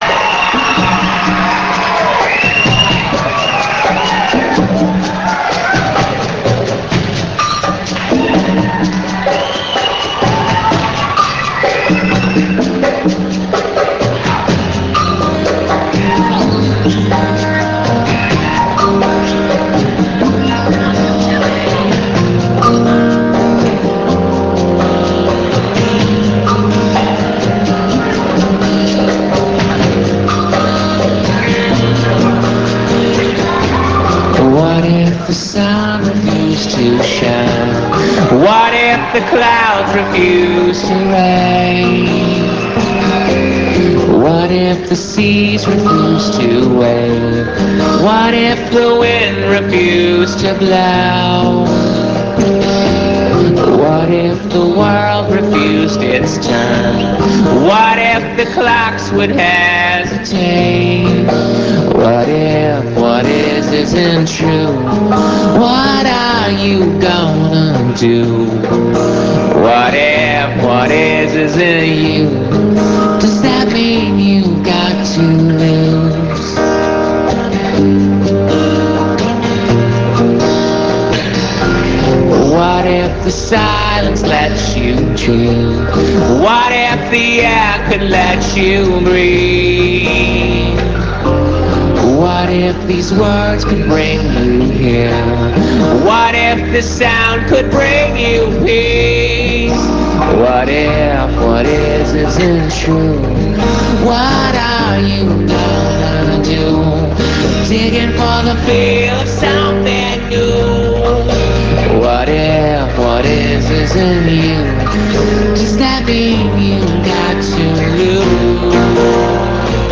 Orpheum Theater; Boston, USA
acoustique. on line